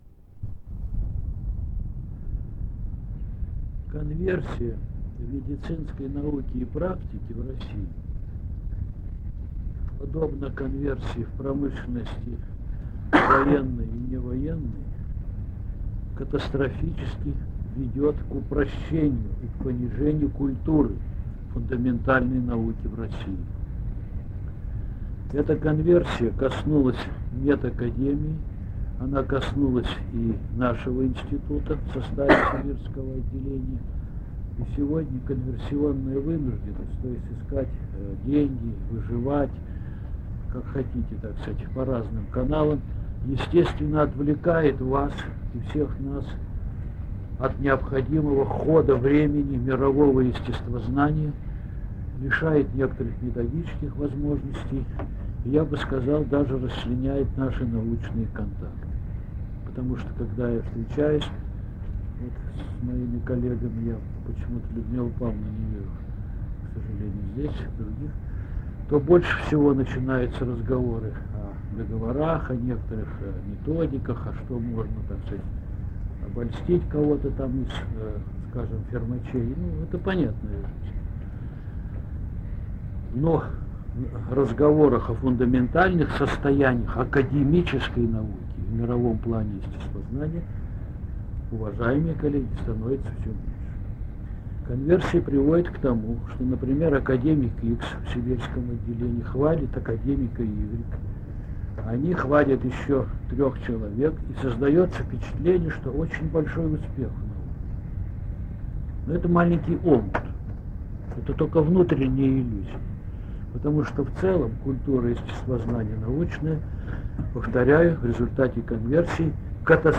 - Устная речь